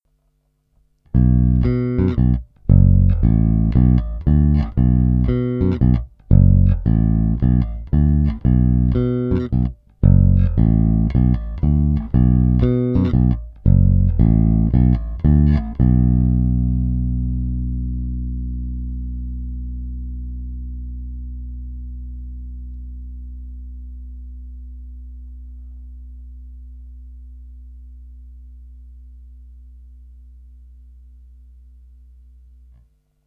I přestože jsou na base půl roku staré struny, což u Elixirů v zásadě nic neznamená, je slyšet, že nové snímače mají o něco mohutnější basy a brilantnější výšky, než ty původní mexické.
Snímač u krku – Super 55